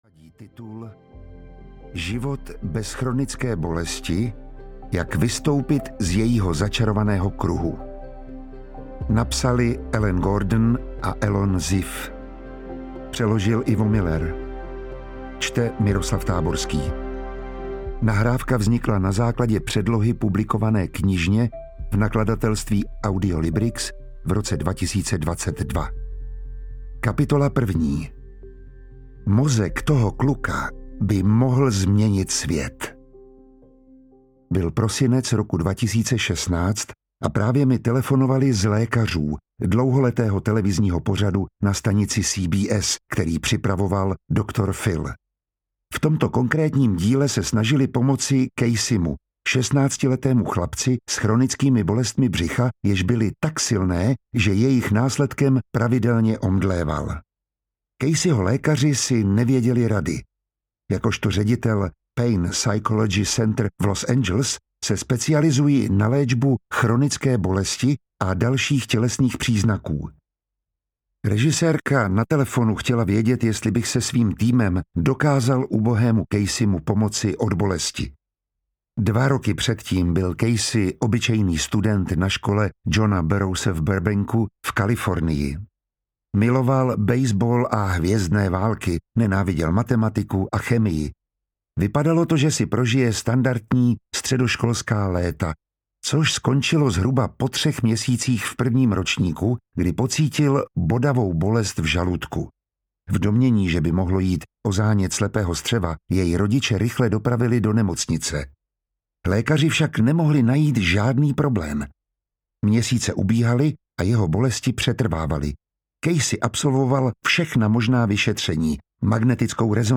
Život bez chronické bolesti audiokniha
Ukázka z knihy
• InterpretMiroslav Táborský